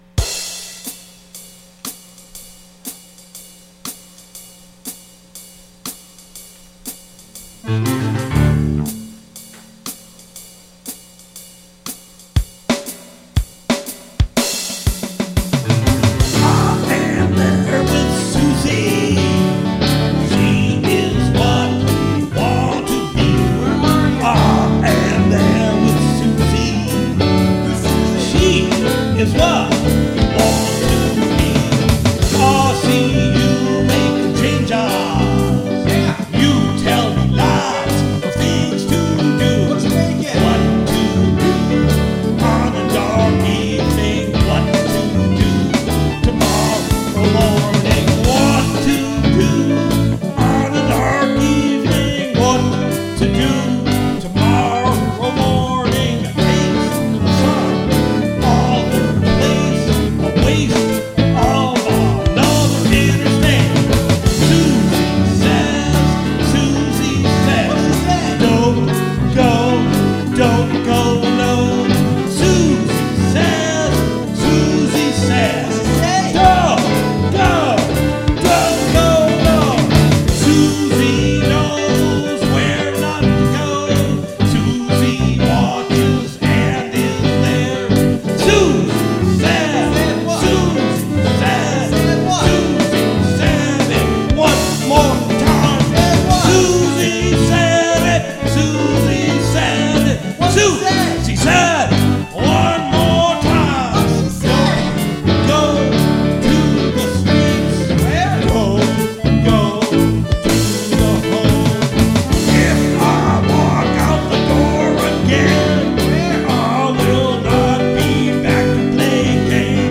Bass Solo